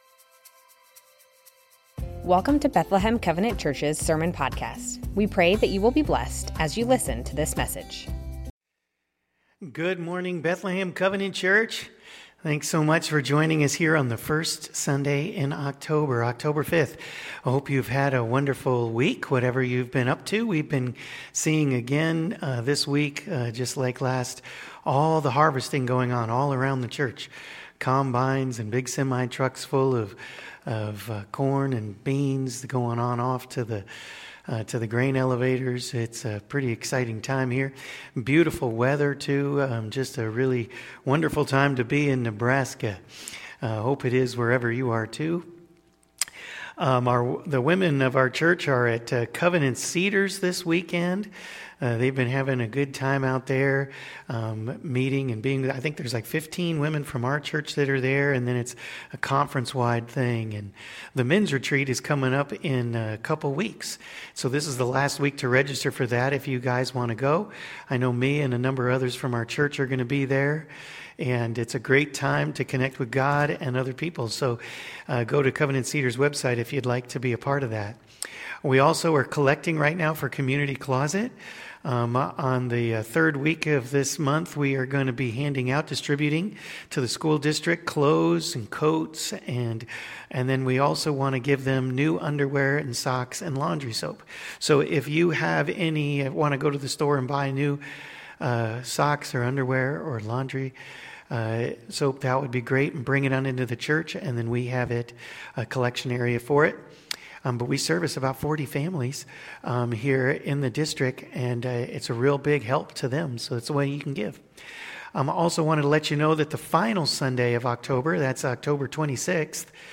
Bethlehem Covenant Church Sermons The Names of God - Jehovah Nissi Oct 05 2025 | 00:37:38 1x 00:00 / 00:37:38 Subscribe Share Spotify RSS Feed Share Link Embed